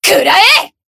BA_V_Neru_Bunny_Battle_Shout_3.ogg